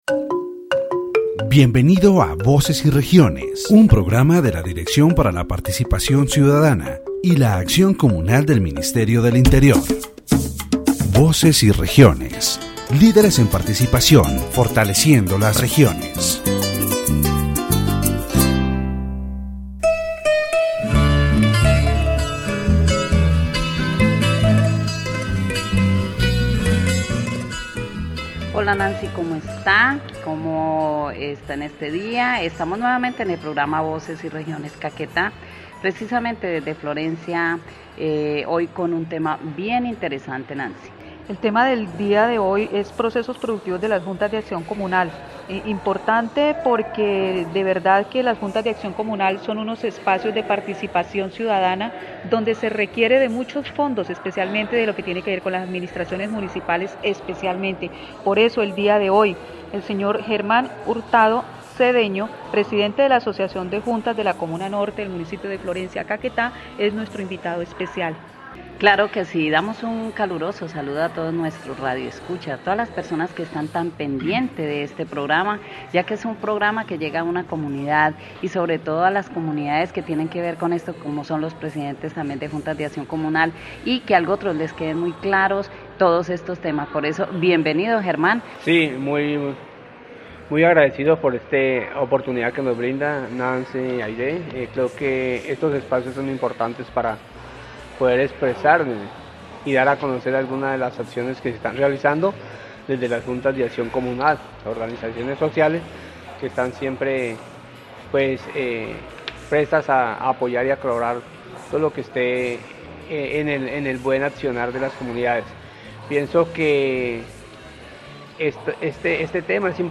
The radio program "Voices and Regions" of the Directorate for Citizen Participation and Communal Action of the Ministry of the Interior focuses on the participatory budget in the department of Caquetá. In this episode, Representative Leidy García González, president of the Budget Commission of the Departmental Assembly of Caquetá, explains how the presidency of the commission is chosen and its importance in the management of projects with fiscal and budgetary impact. The department's fiscal limitations due to past debts and the need to pay off these debts before being able to invest in social projects are discussed.